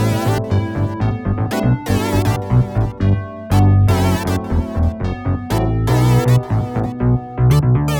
34 Backing PT2.wav